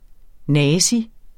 Udtale [ ˈnæːsi ]